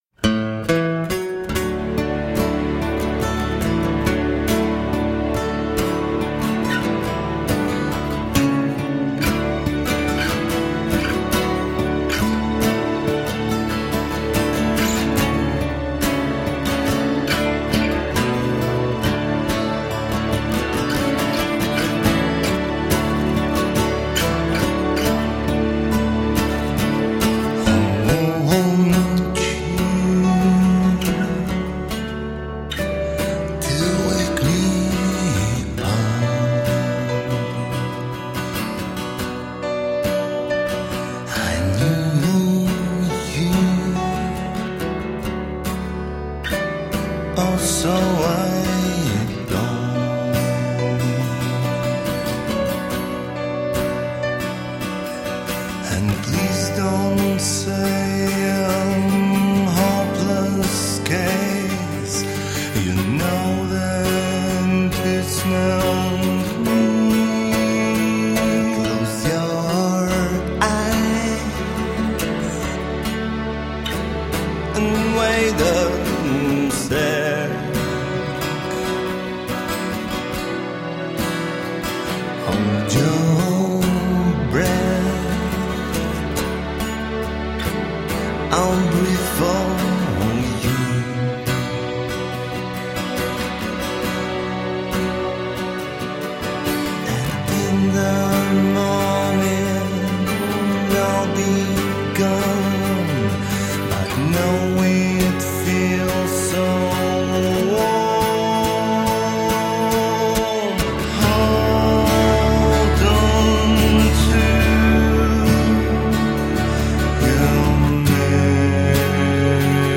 Rock, alternative rock, indie rock, psych rock.
Tagged as: Alt Rock, Rock, Hard Rock, Prog Rock